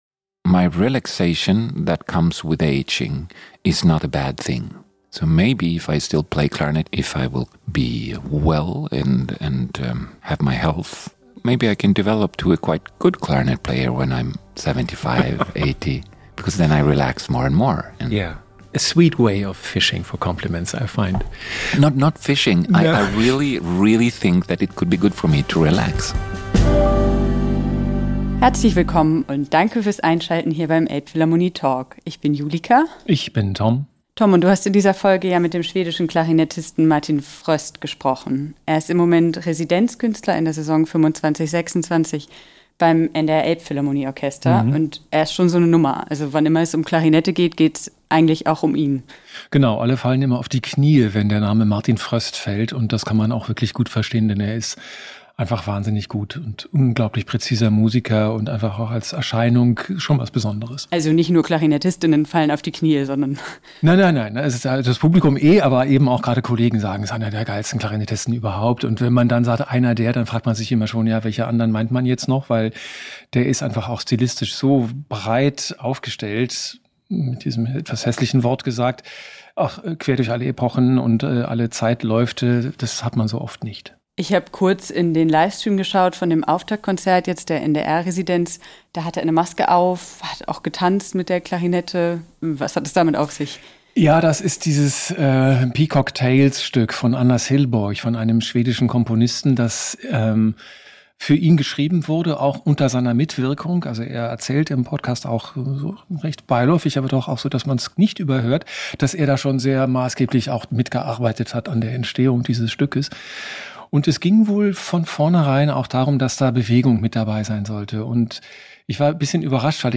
Was hat der mit ABBA zu tun? Im Gespräch mit dem schwedischen Klarinetten-Star Martin Fröst